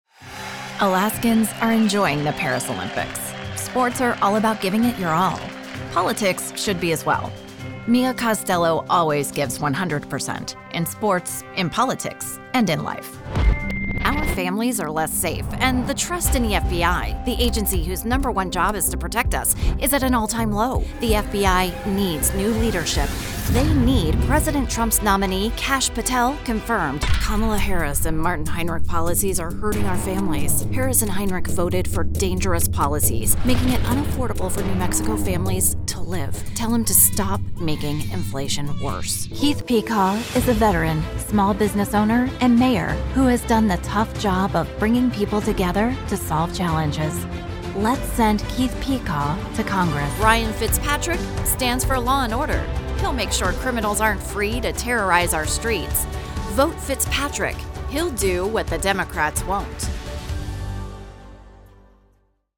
Female Republican Voices
Variety of great voice actors with pro home studios and Source Connect.